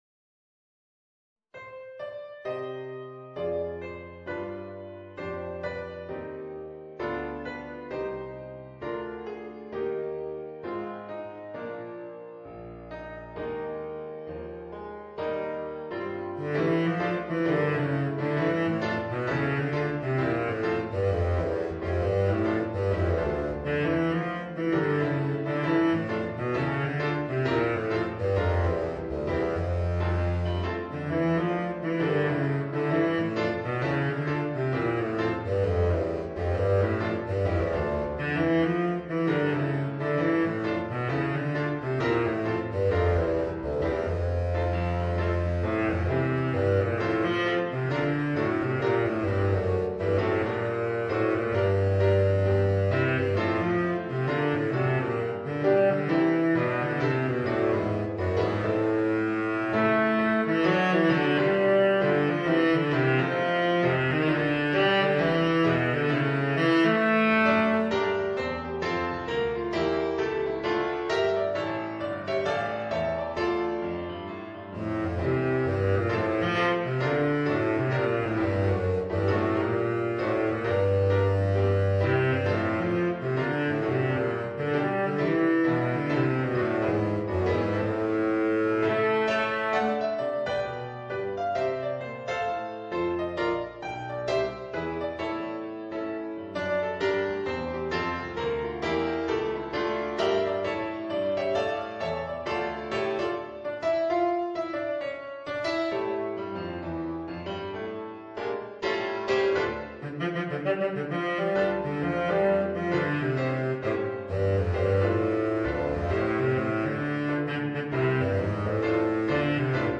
Voicing: Baritone Saxophone and Piano